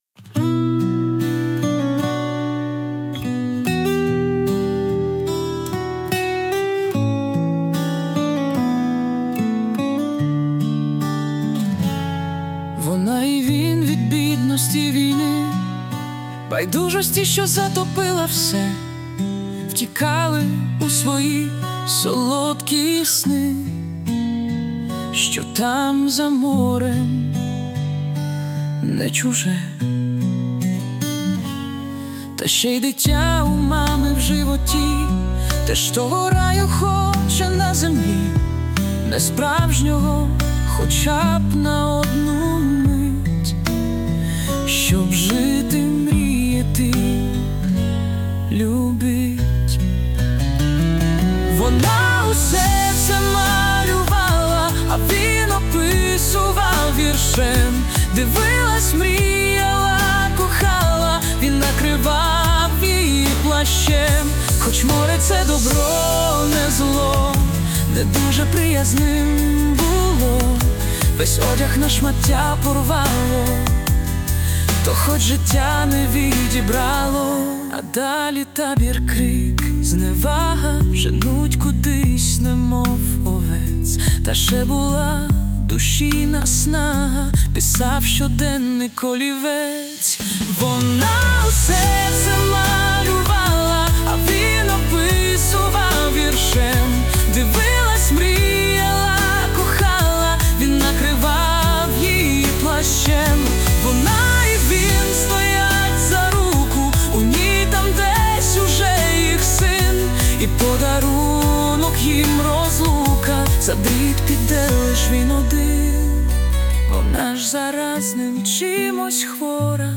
СТИЛЬОВІ ЖАНРИ: Ліричний
12 12 16 Прекрасні слова! 16 Майстерно підібрана музика! friends Вона усе це малюва́ла,